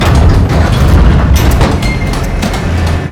dock.wav